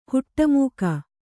♪ huṭṭa mūka